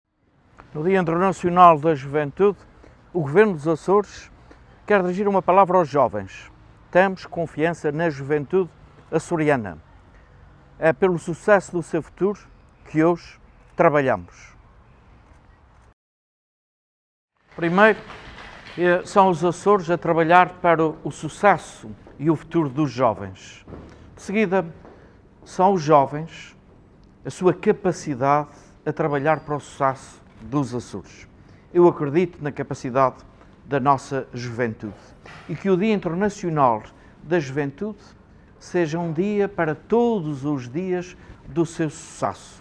O Presidente do Governo Regional dos Açores, José Manuel Bolieiro, reforçou a sua confiança na juventude açoriana através de uma mensagem gravada para assinalar o Dia Internacional da Juventude, que hoje se assinala.